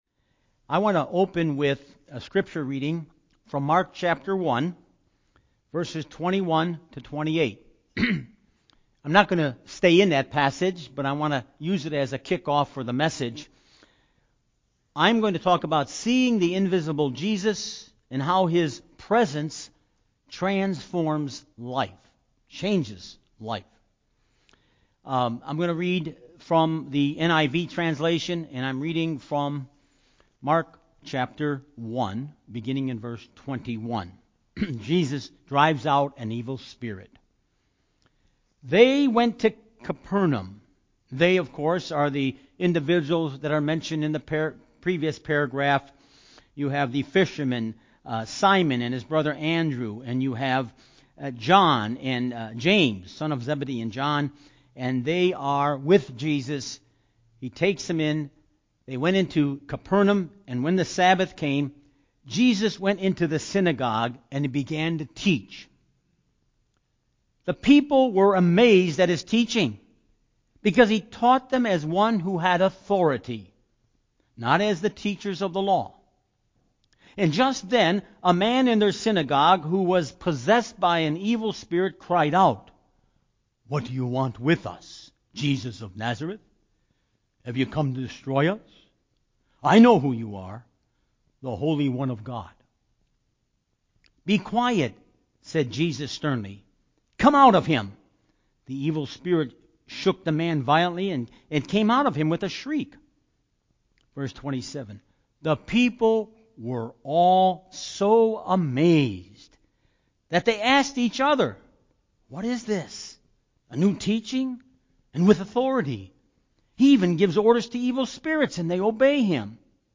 Palm Sunday message
Service Type: Sunday morning